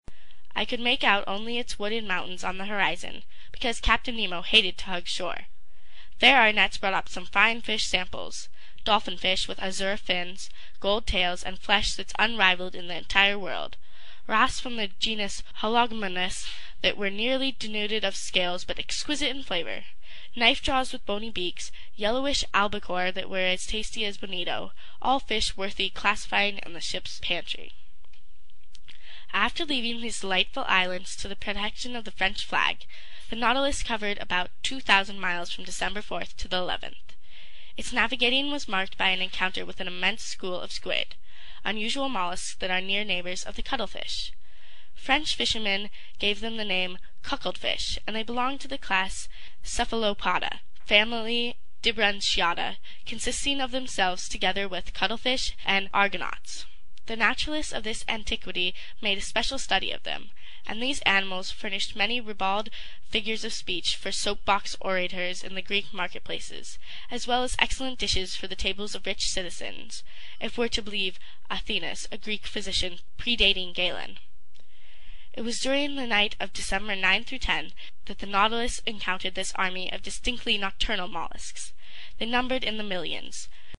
英语听书《海底两万里》第236期 第18章 太平洋下四千里(8) 听力文件下载—在线英语听力室